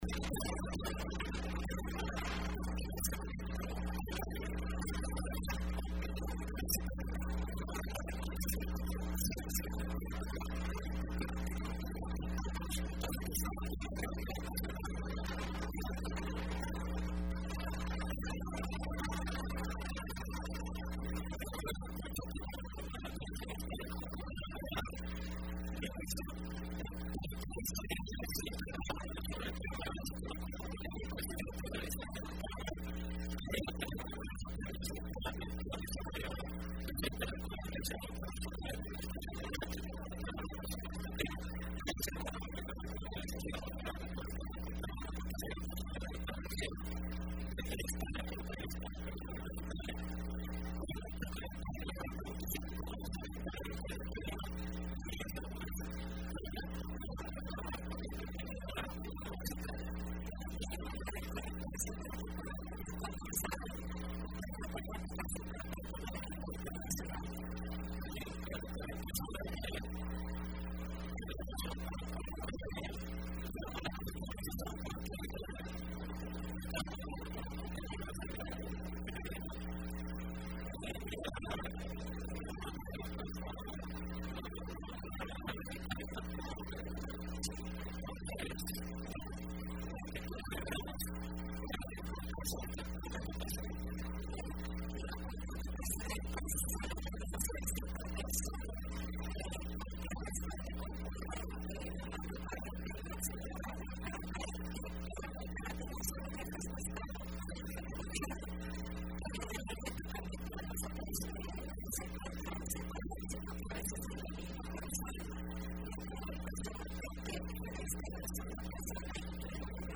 Entrevista Opinión Universitaria (27 de abril de 2015): Séptima Cumbre de presidentes